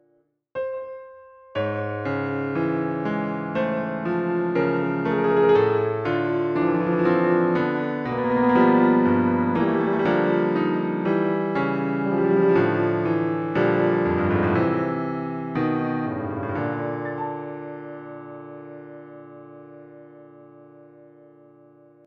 Idea no.14 - Piano Music, Solo Keyboard - Young Composers Music Forum